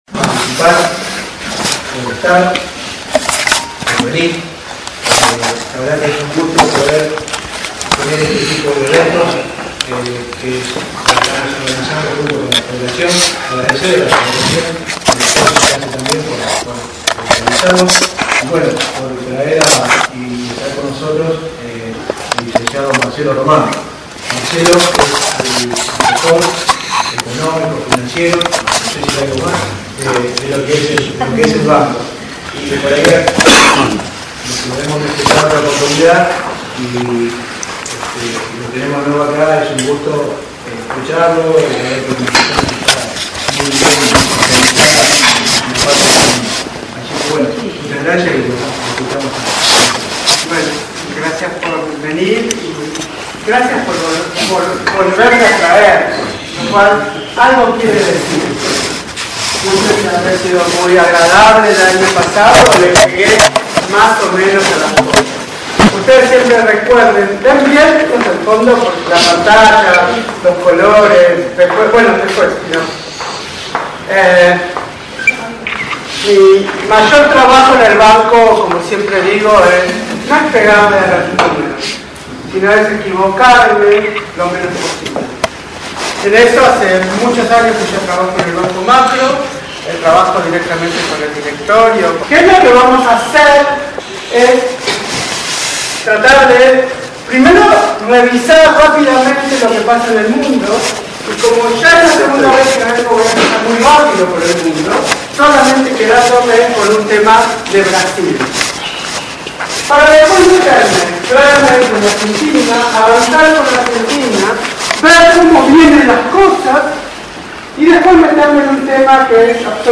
La misma  sucedió el 18 de Julio y se realizó en el salón centenario de la institución.